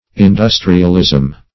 Industrialism \In*dus"tri*al*ism\, n.